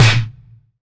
Snare (Blow).wav